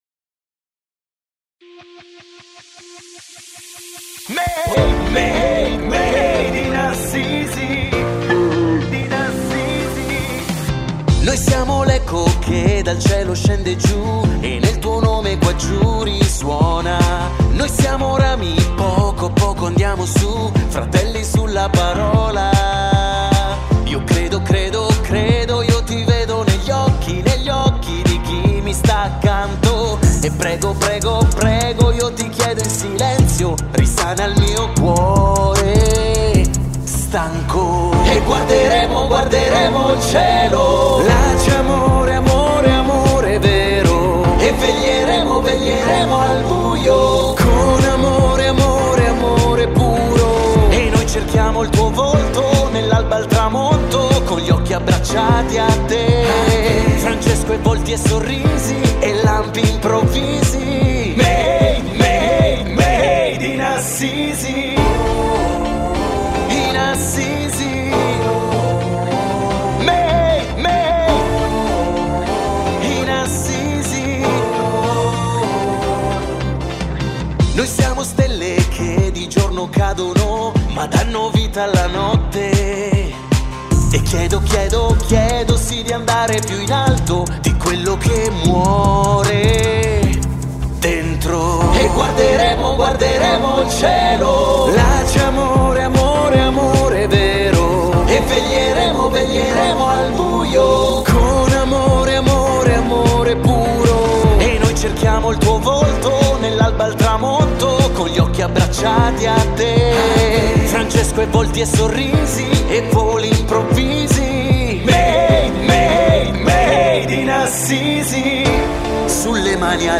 L’inno dei campi estivi
MADE-IN-ASSISI-INNO.mp3